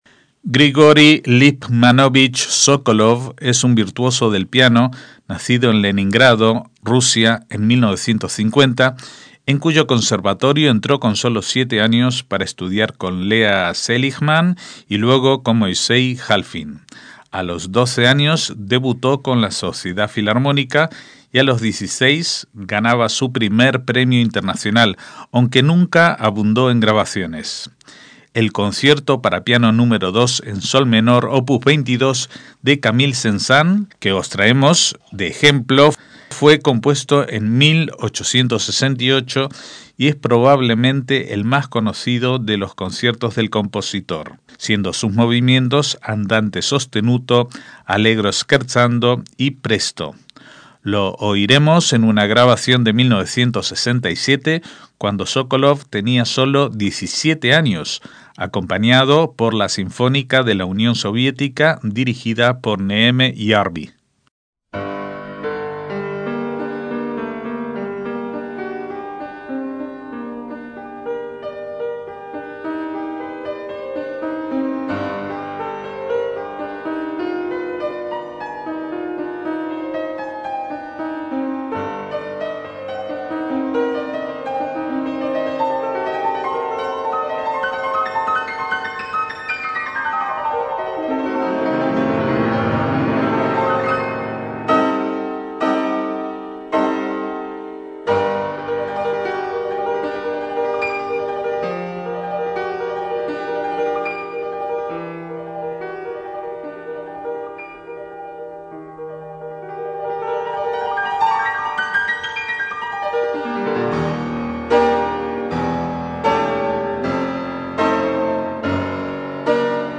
MÚSICA CLÁSICA - Grigori Sokolov es un pianista ruso, nacionalizado español, nacido en 1950, ganador del Concurso Tchaikovsky de 1966, aunque no fue hasta la década de los 80 que despegó realmente su carrera.